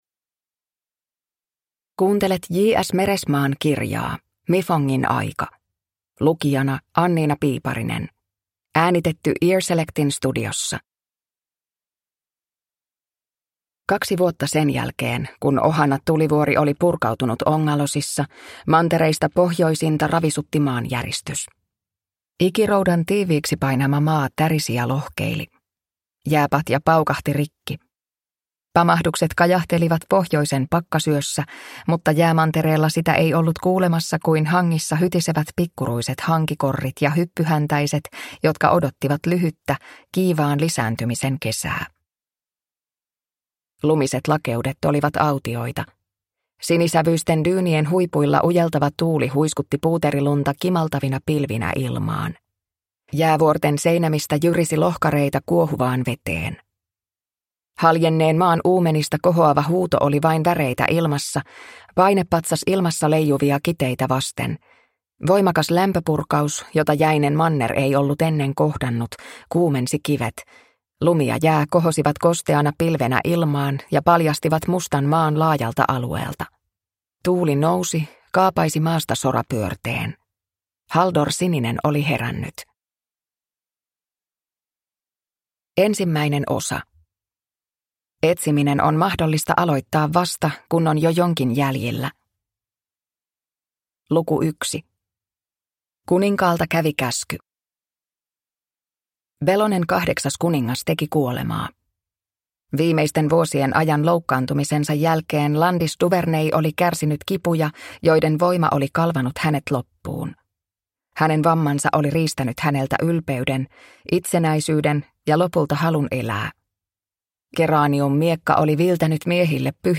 Mifongin aika – Ljudbok